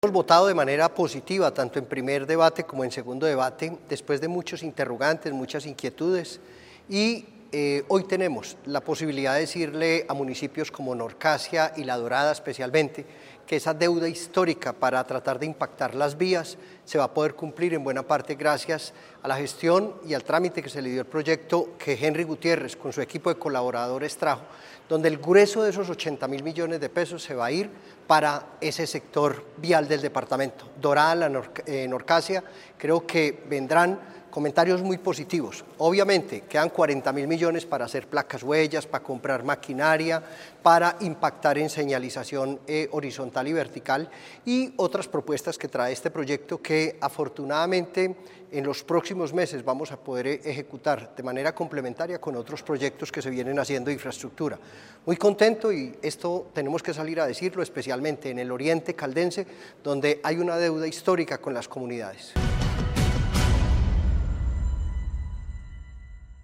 Diputado de Caldas, Jorge Hernán Aguirre.